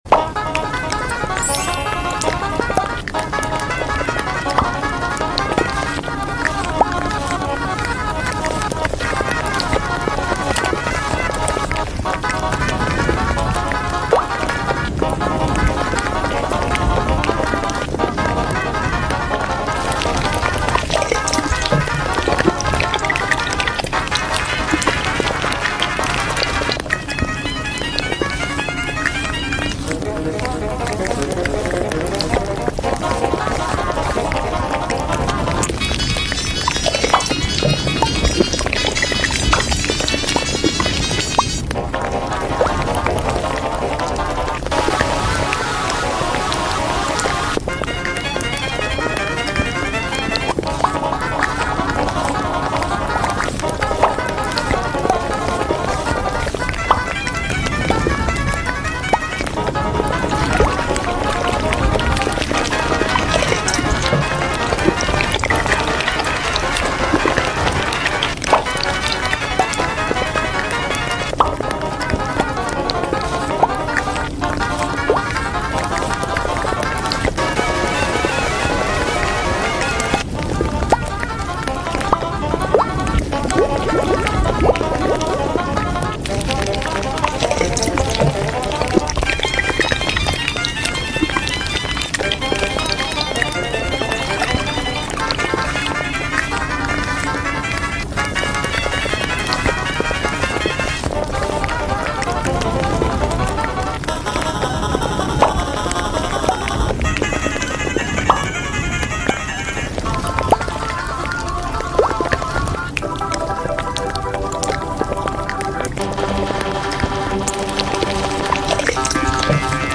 Roblox eating with blob emoji sound effects free download